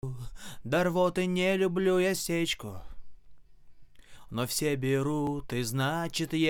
Что это за артефакт при записи?Проблемы при записи
Не так давно началась такая проблема, какие артефакты при записи...Может быть наводки какие то? причем вокала, инструментов без разницы.
Кто с таким сталкивался или знает, в чем может быть дело? на словосочетание ВСЕ БЕРУТ...слышно, треск Вложения запись голос.mp3 запись голос.mp3 152,5 KB · Просмотры: 2.444